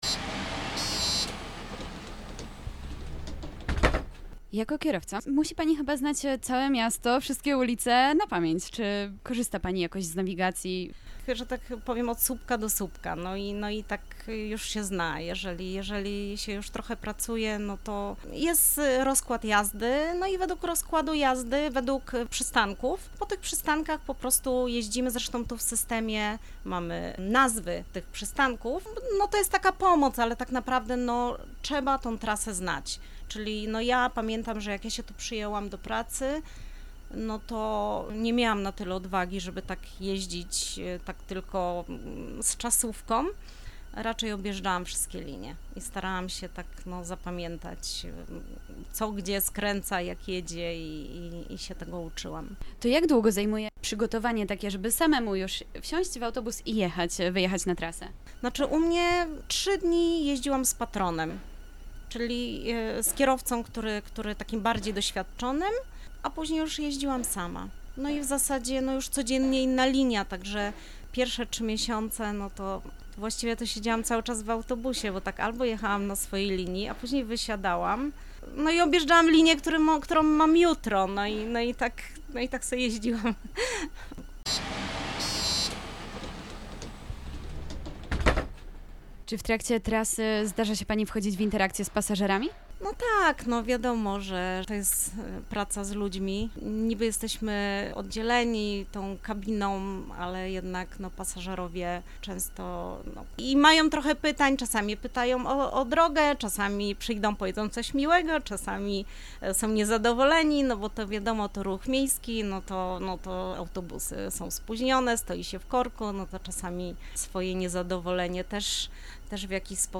Radio Rodzina odwiedziło zajezdnię autobusową przy ul. Obornickiej i porozmawiało o tych i innych tematach z pracownikami MPK Wrocław.